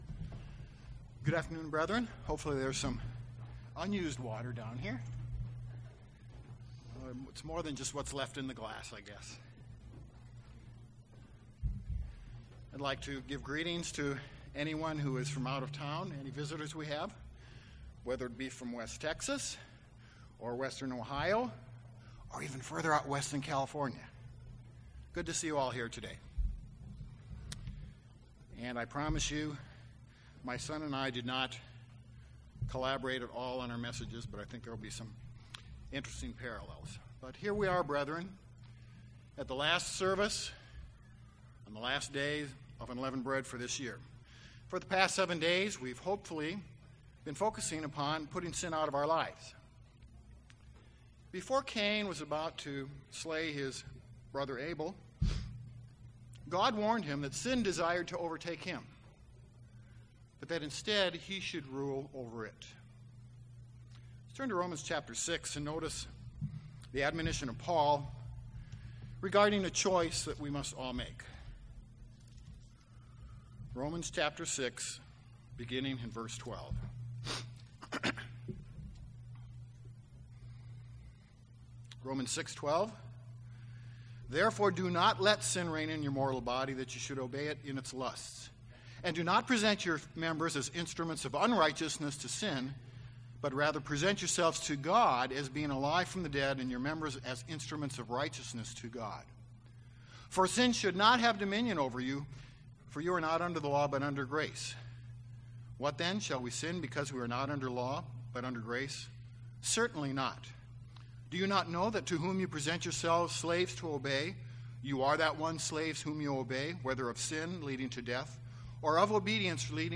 The purpose of this sermon is to address the reality of sin in our lives ... after observing the days of UB each year, we then become unleavened spiritually. We will examine and analyze both ineffective means and effective means for dealing with sin in our lives.
Given in Dallas, TX
UCG Sermon Studying the bible?